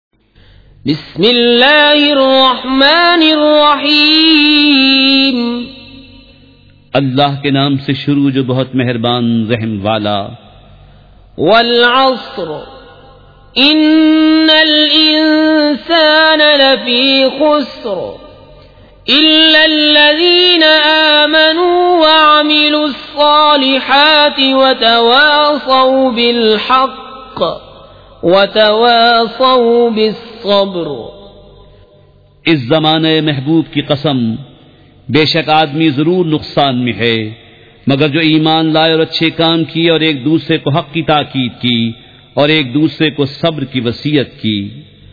سورۃ العصر مع ترجمہ کنزالایمان ZiaeTaiba Audio میڈیا کی معلومات نام سورۃ العصر مع ترجمہ کنزالایمان موضوع تلاوت آواز دیگر زبان عربی کل نتائج 2440 قسم آڈیو ڈاؤن لوڈ MP 3 ڈاؤن لوڈ MP 4 متعلقہ تجویزوآراء